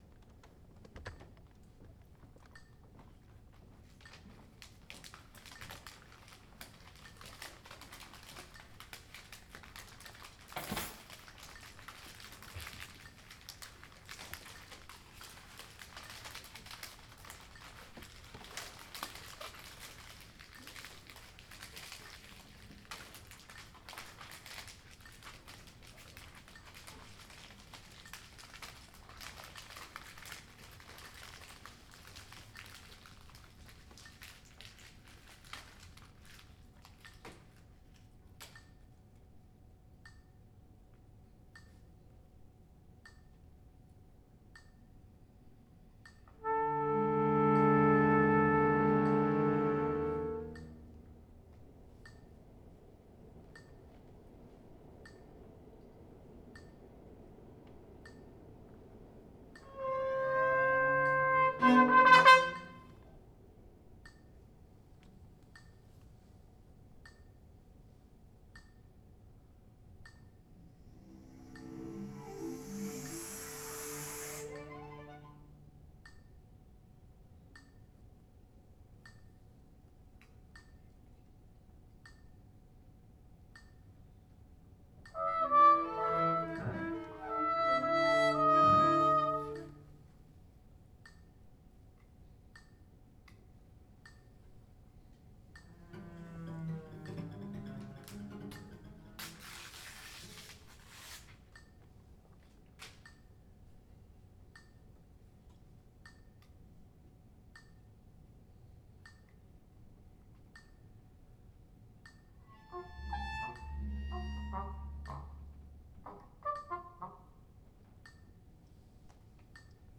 for trumpet, cello, acoustic bass and metronome